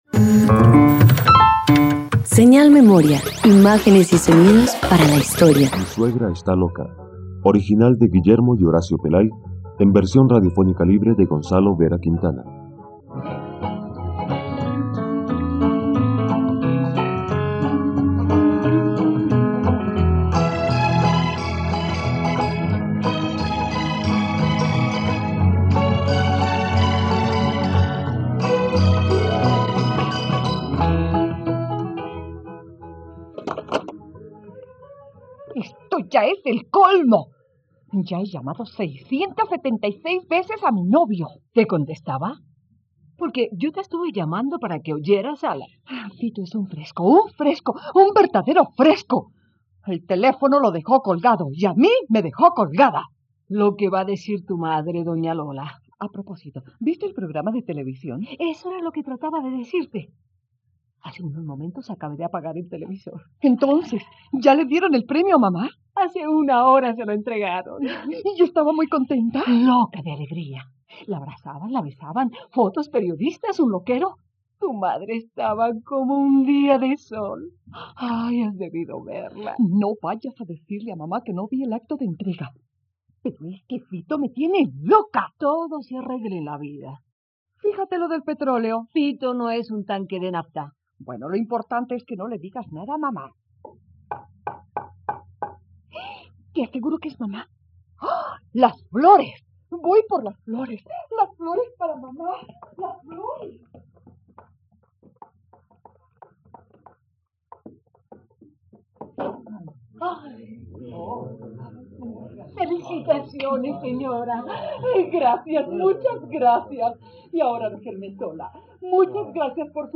Una novia enamorada, una madre celosa, un matrimonio arreglado, una traición y una amenaza de divorcio acompañan a Lola, Viri y Fito, los protagonistas de esta adaptación para radio de la comedia escrita por los hermanos Guillermo y Horacio Pelay.